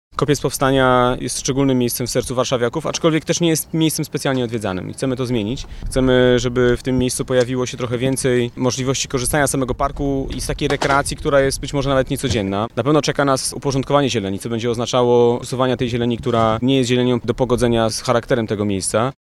– Obecnie są to tereny zielone, ale nie w całości zagospodarowane. Czas na ich renowację – mówi wiceprezydent Warszawy Michał Olszewski.